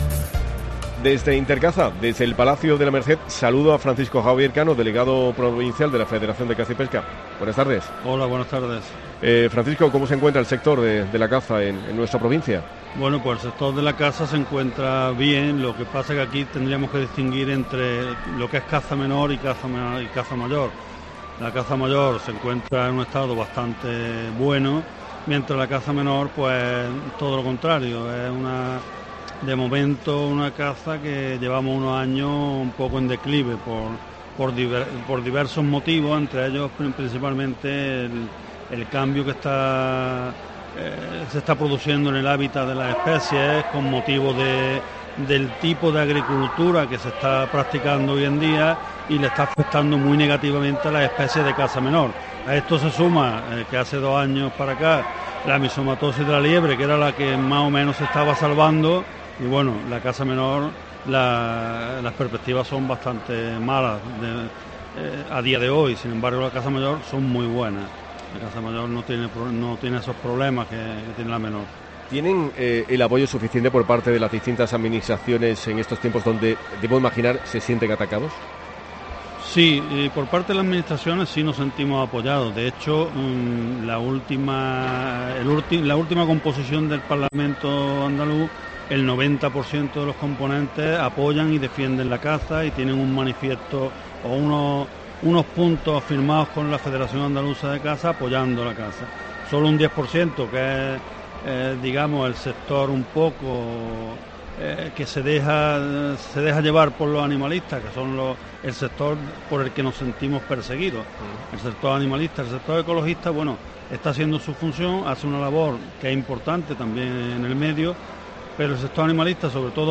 Por el set de radio de COPE hoy han pasado distintos protagonistas para contarnos cómo se organiza, quiénes lo forman, cómo están los distintos sectores y qué actividades se han programado para esta nueva edición de Intercaza 2019, la Feria Cinegética, de Turismo, Ocio Activo y Medio Ambiente.